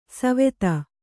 ♪ saveta